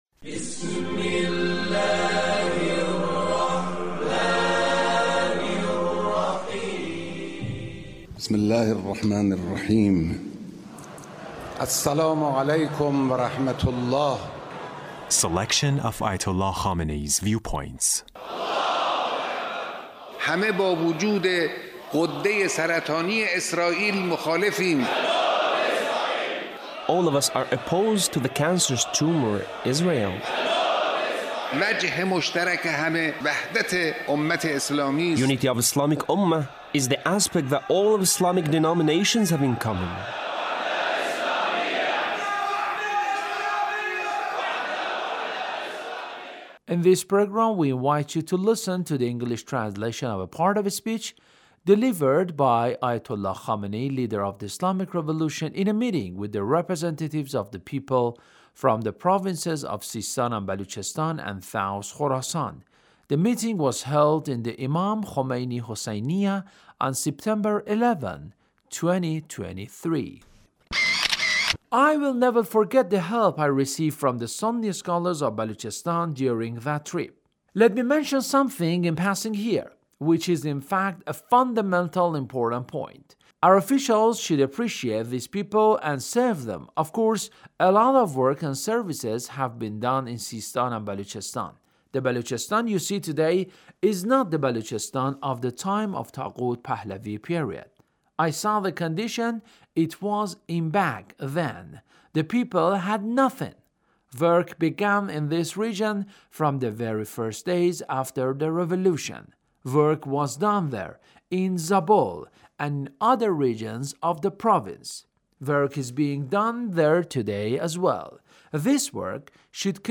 Leader's Speech (1832)